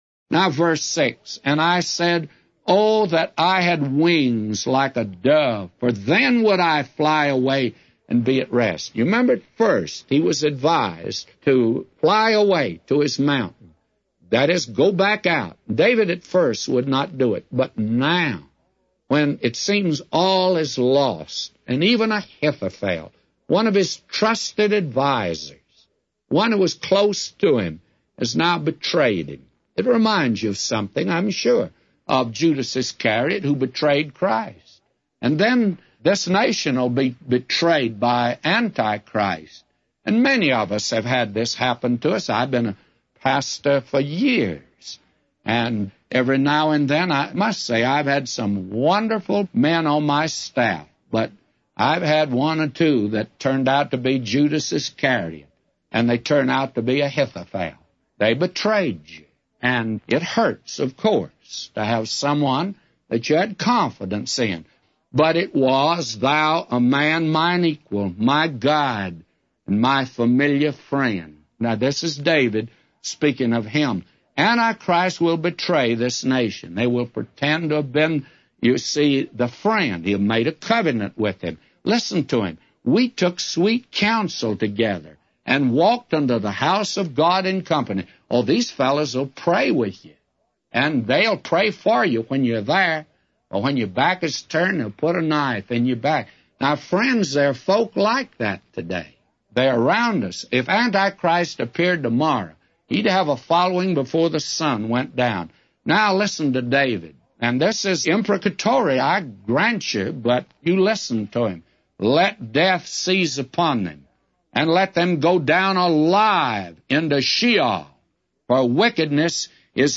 A Commentary By J Vernon MCgee For Psalms 55:6-999